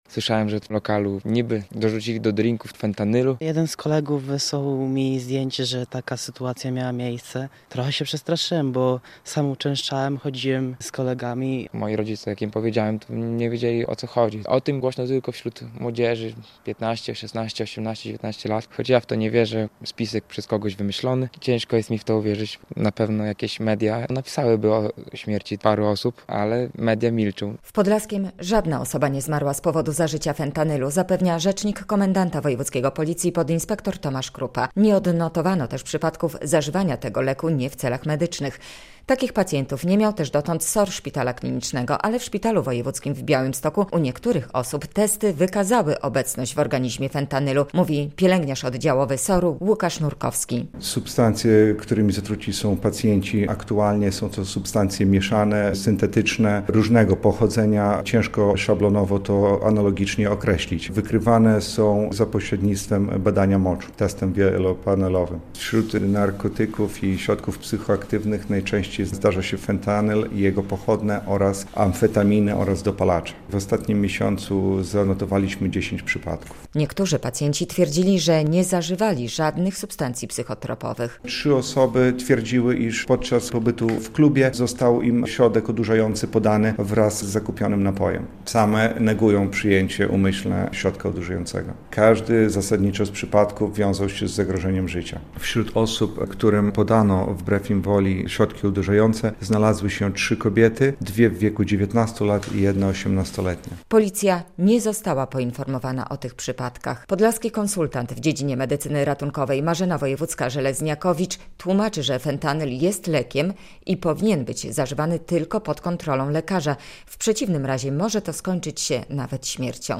Niebezpieczeństwa z powodu zażywania fentanylu i innych leków - relacja